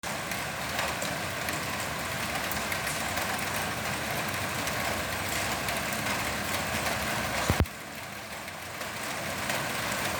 (320.29 KB, rain.mp3)
Дождииик.
И на крыше слышно хорошо.